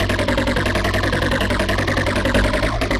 Index of /musicradar/future-rave-samples/160bpm
FR_Boingo_160-G.wav